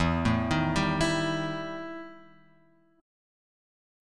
EmM7bb5 chord {0 0 1 0 x 0} chord